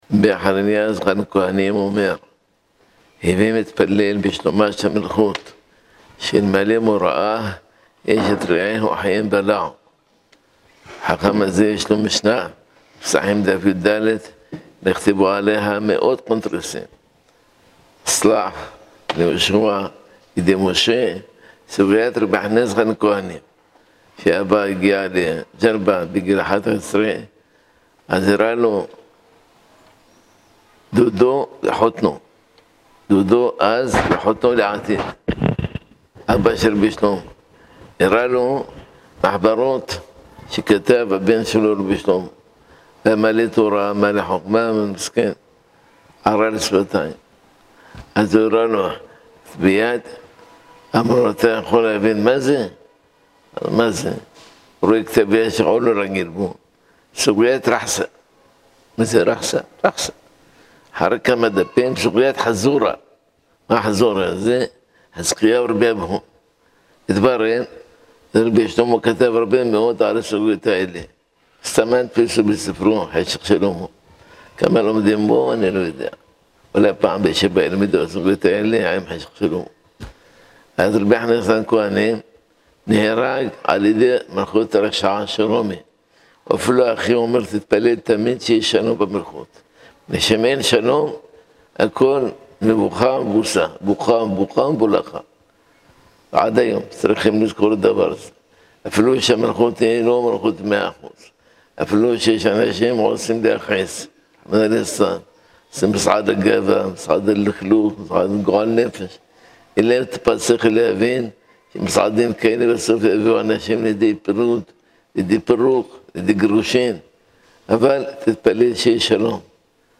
השיעור השבועי המשודר ברדיו קול ברמה מידי יום ראשון בשעה 20:30 (השיעור נבחר מתוך מאגר השיעורים של גאון ישראל רבנו הגדול מרן ראש הישיבה רבנו מאיר נסים מאזוז זצוק”ל).